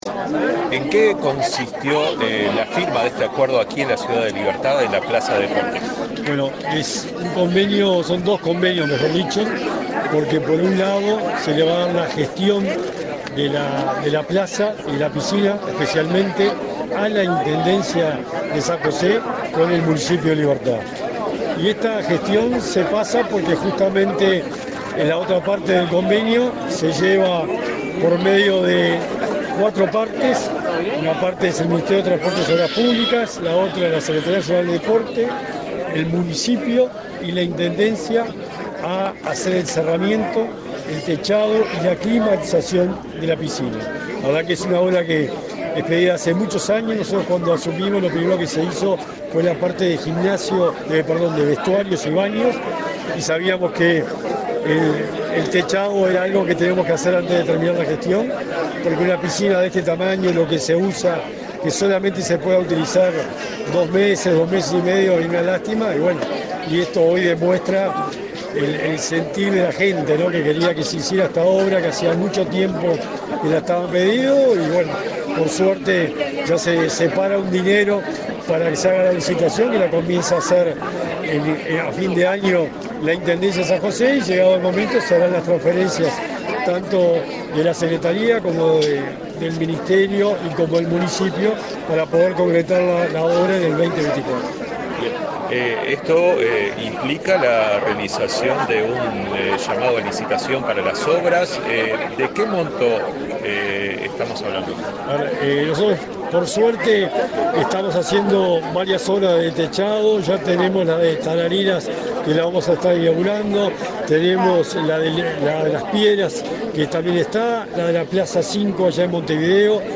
Entrevista al secretario de Deporte, Sebastián Bauzá